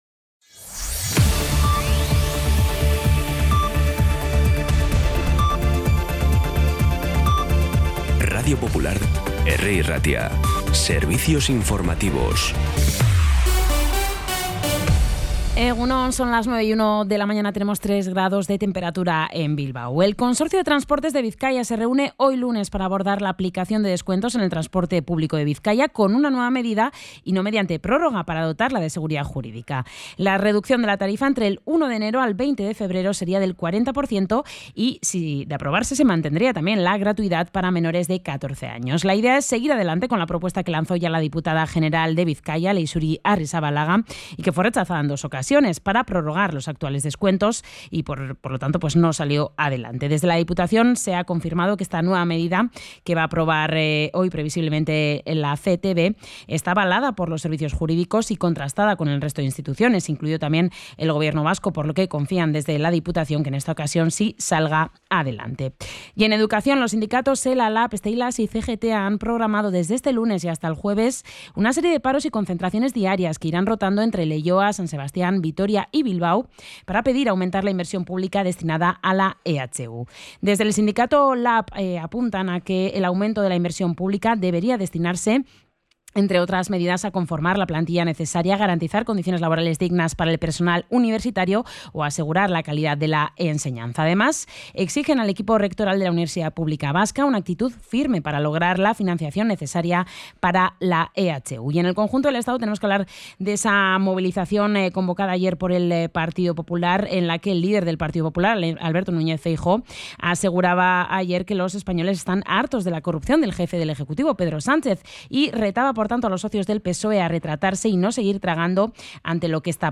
Las noticias de Bilbao y Bizkaia de las 9 , hoy 1 de diciembre
Los titulares actualizados con las voces del día. Bilbao, Bizkaia, comarcas, política, sociedad, cultura, sucesos, información de servicio público.